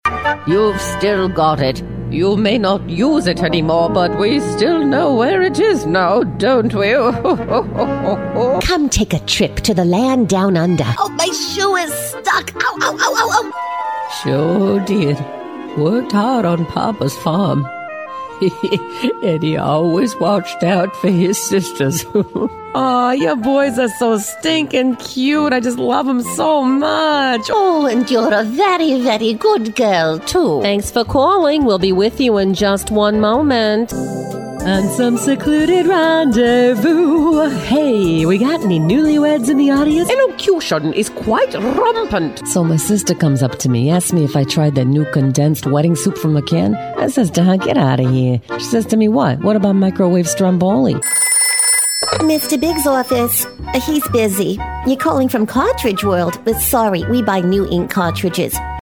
Warm, melodic, rich, and resonant - from sparking to sexy.
englisch (us)
Sprechprobe: Sonstiges (Muttersprache):